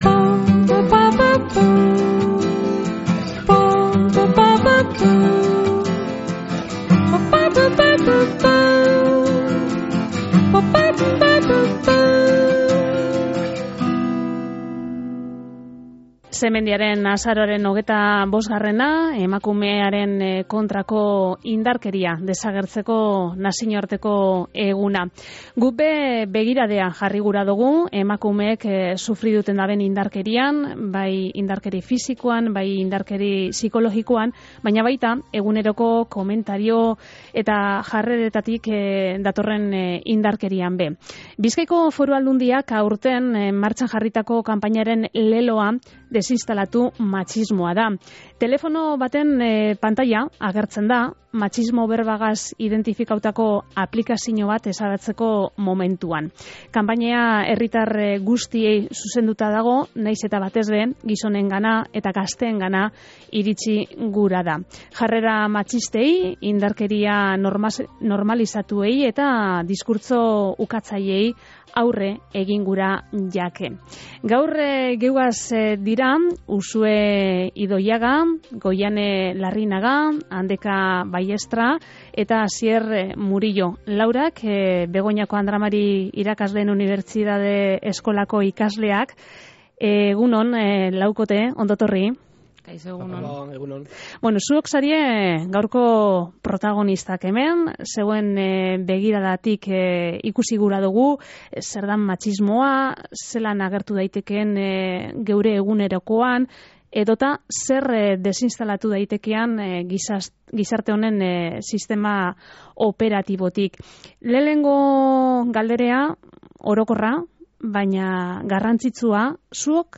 Emakumearen Kontrako Indarkeriaren Aurkako Nazinoarteko Egunaren harira, lau gaztek euren esperientziak partekatu deuskuez
Z25-GAZTEEN-TERTULIA.mp3